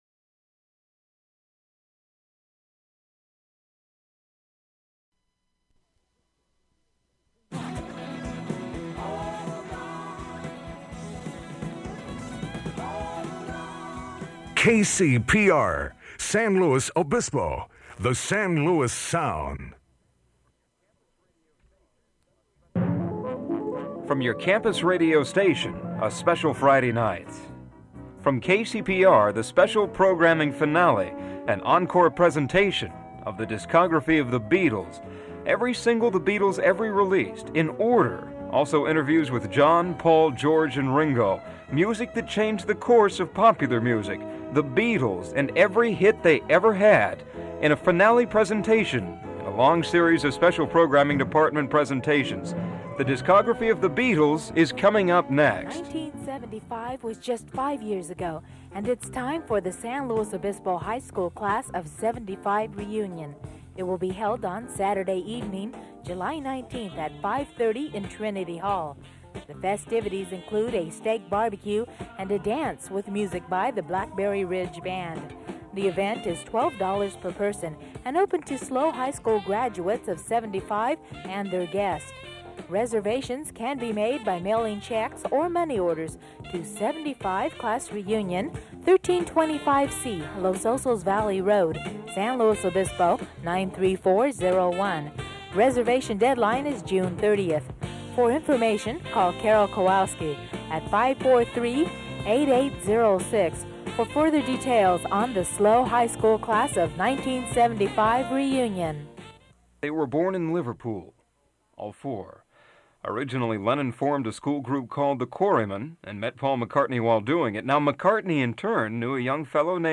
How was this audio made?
Recordings of Beatles' songs have been removed from this online copy.